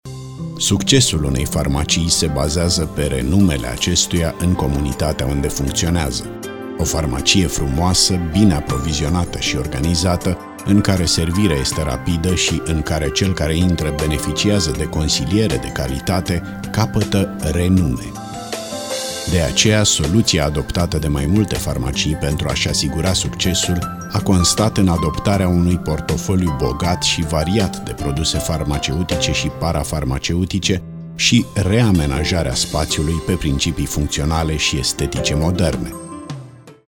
Experienced Voice Over, Romanian native
Kein Dialekt
Sprechprobe: Sonstiges (Muttersprache):